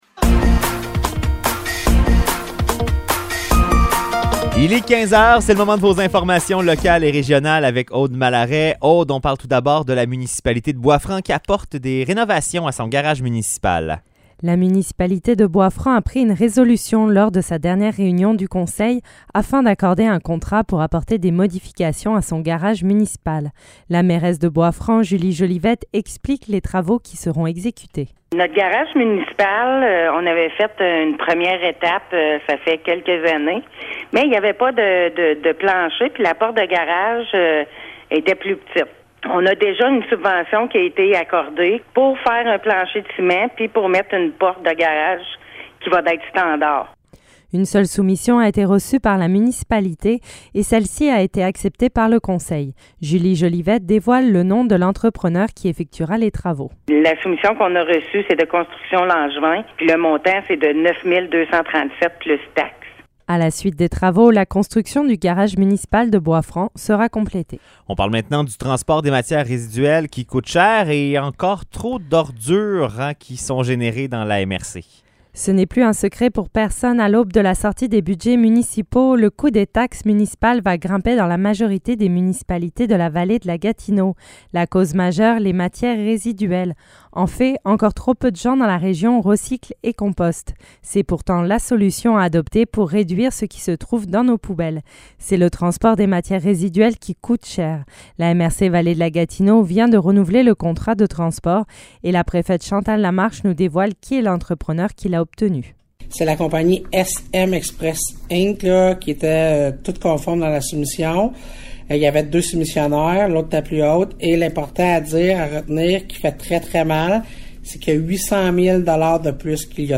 Nouvelles locales - 16 décembre 2022 - 15 h